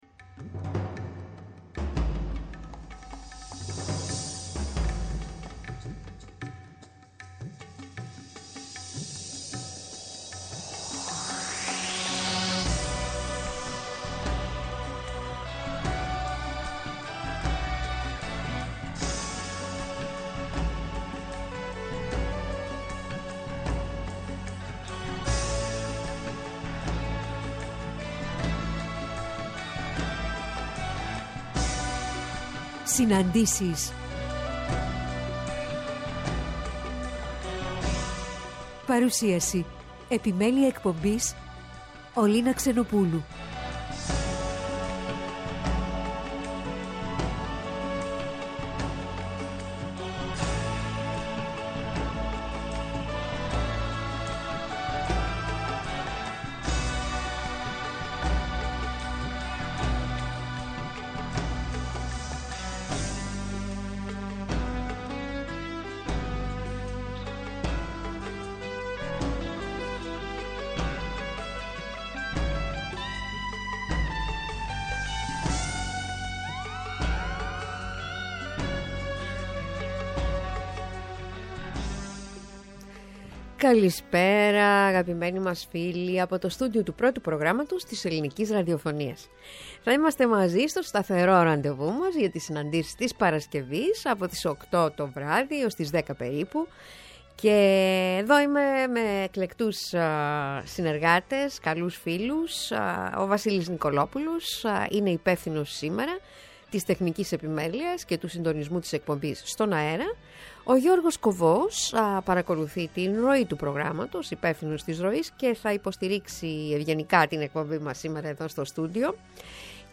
Στις Συναντήσεις απόψε 20:00-22:00 καλεσμένοι :